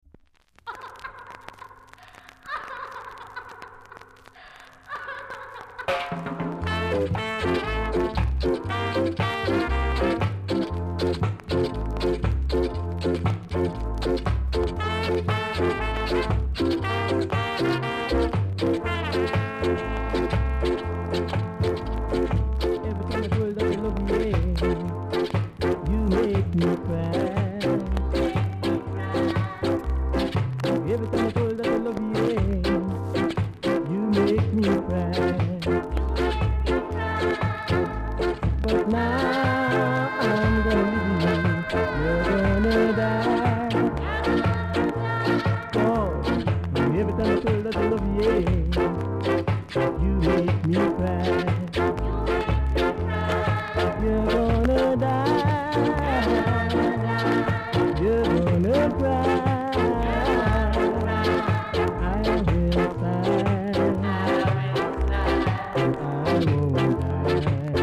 ※出だしでジリジリします。全体的に単発でチリ、パチノイズがあります。
主観ですが音圧あるので人前でのプレイはイケそうかなと思います。
コメント 泣きのSKA INST!!!VERY RARE JA PRESS!!